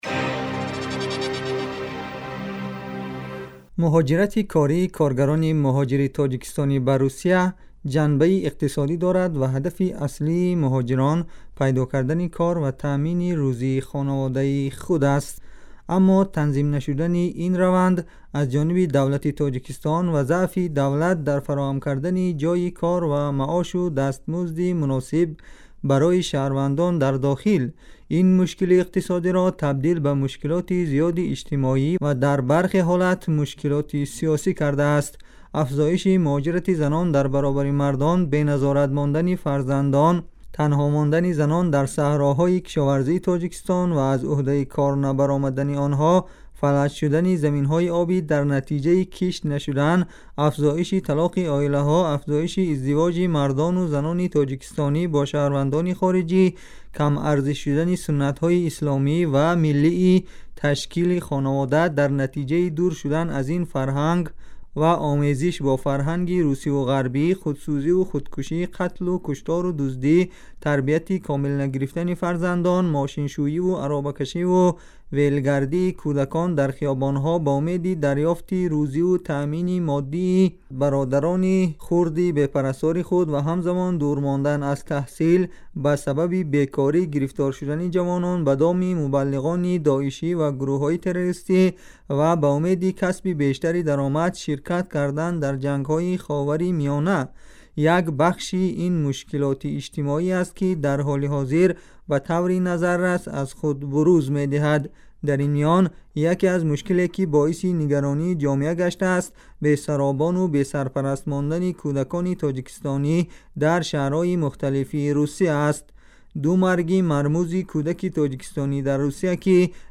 گزارش ویژه- راه بسته بازگشت برای کودکان تاجیکستانی در مهاجرت کاری